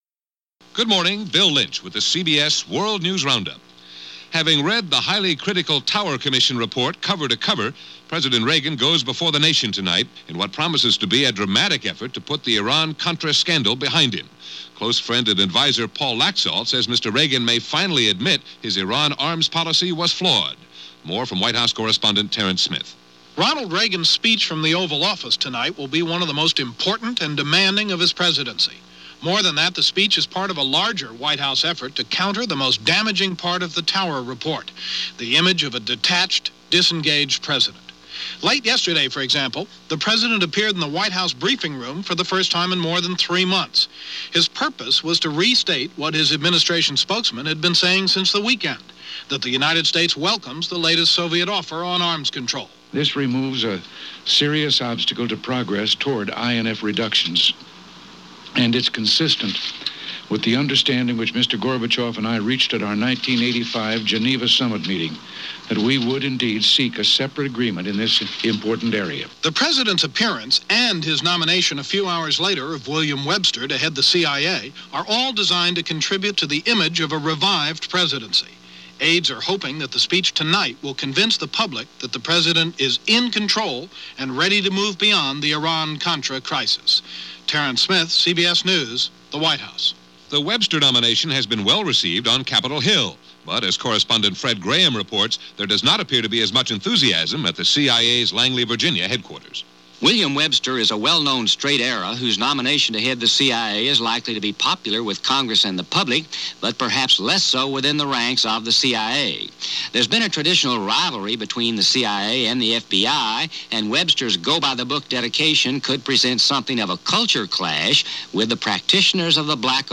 March 4, 1987 – CBS World News Roundup – Gordon Skene Sound Collection –